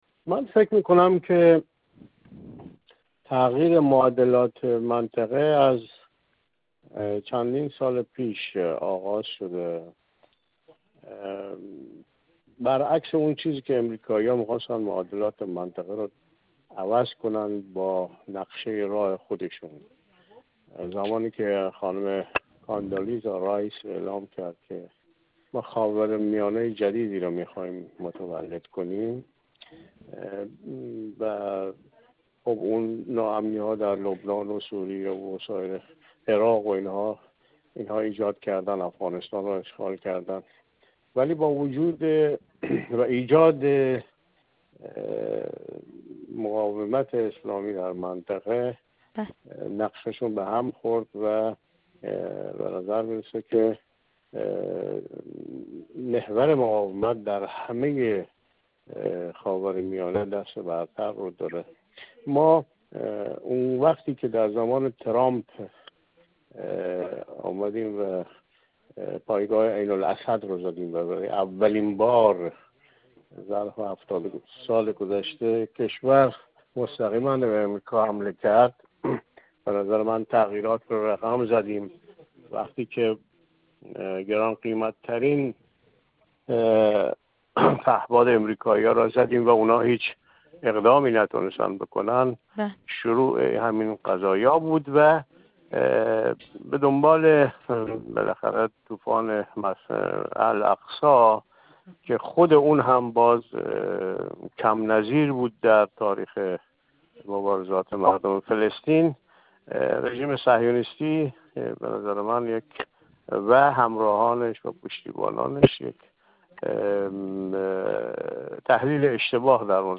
کارشناس مسائل سیاسی
گفت‌وگو